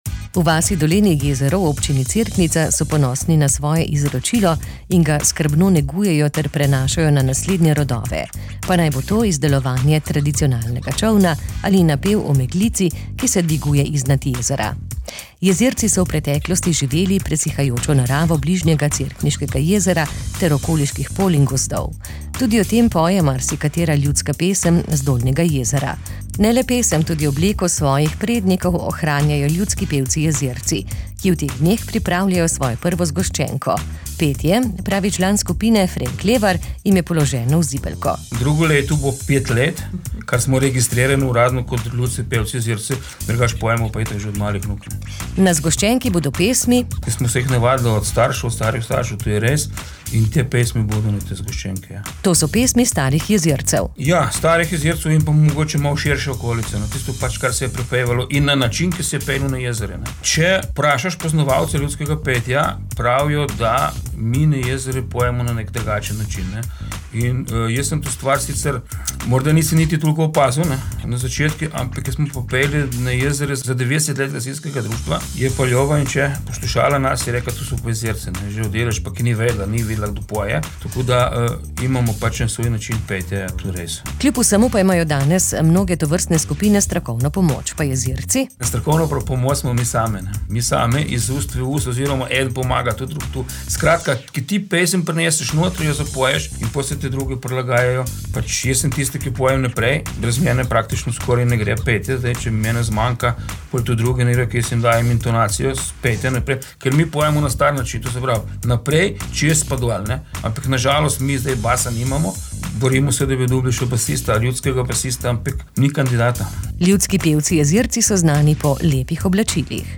kr18-jezerci-izdajajo-zgoscenko.mp3